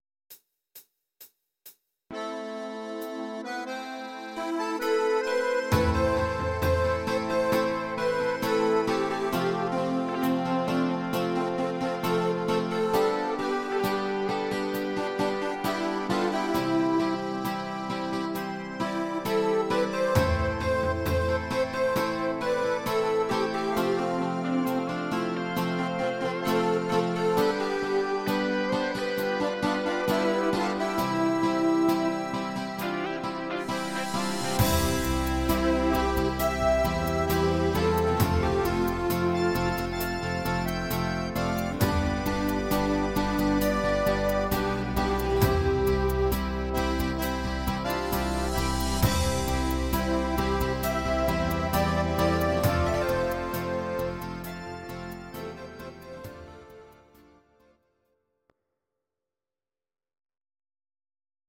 Audio Recordings based on Midi-files
Our Suggestions, Pop, German, 2010s